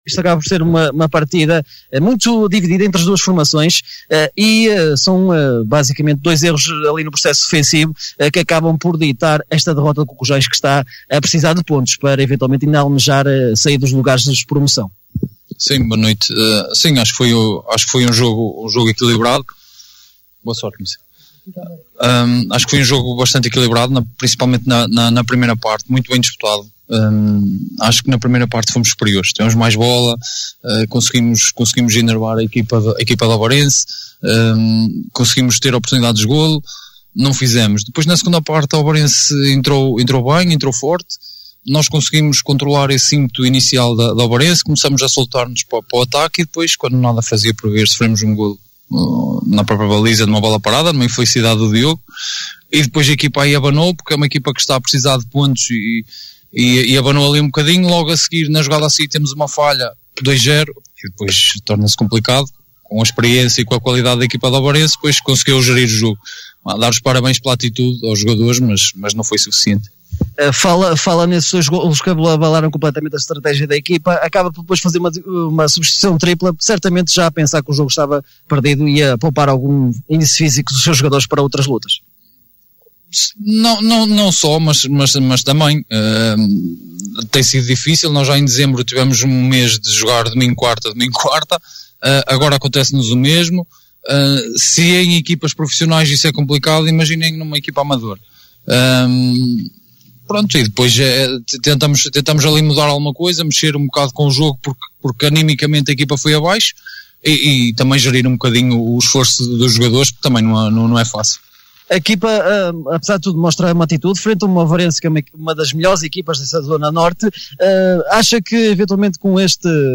Convidamo-lo a ouvir as declarações dos técnicos no fecho da partida que opôs Cucujães e Ovarense.